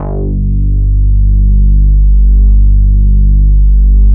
87 MOOG BASS.wav